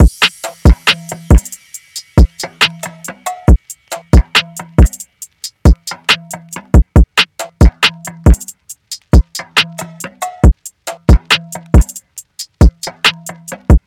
DRUM LOOPS
Curious (138 BPM – Am)
UNISON_DRUMLOOP_Curious-138-BPM-Am.mp3